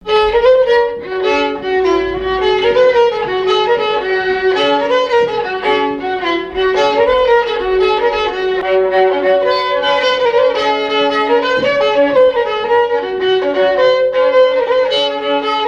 Chants brefs - A danser
Bocage vendéen
danse : branle : avant-deux
Pièce musicale éditée